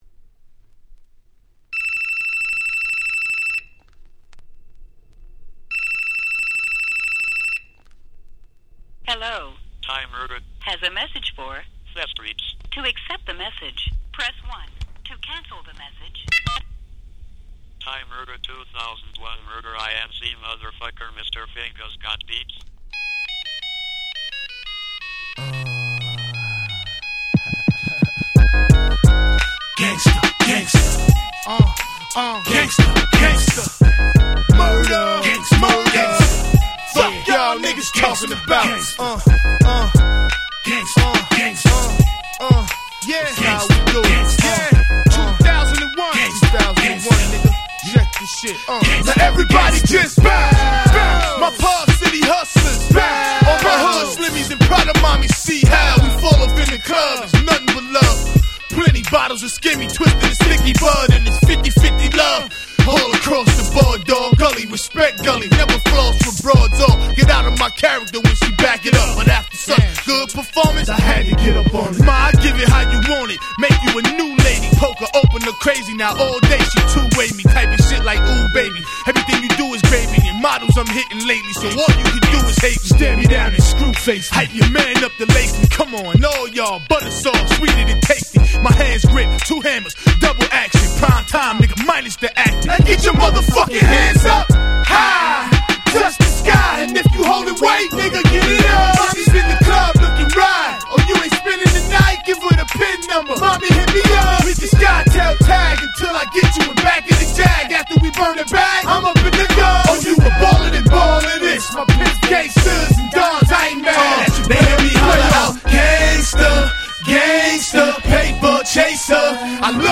01' Smash Hit Hip Hop !!
携帯の着信音をサンプリングした格好良い1曲。